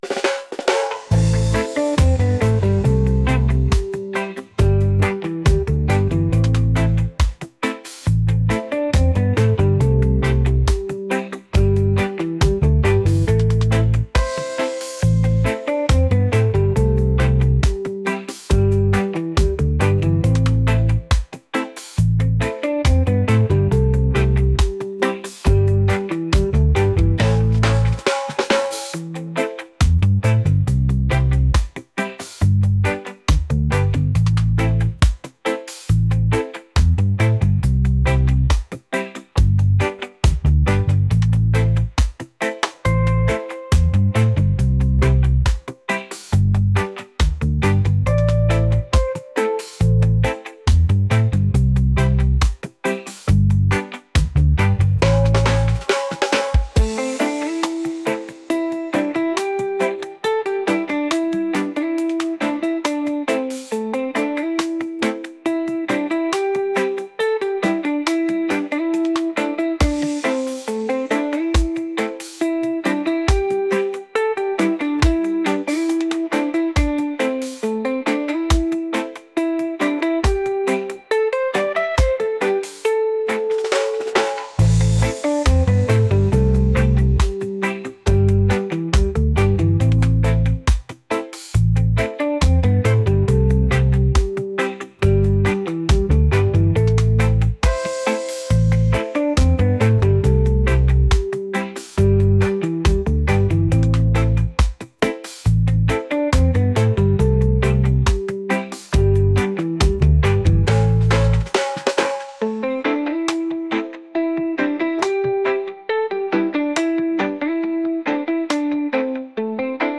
upbeat | groovy | reggae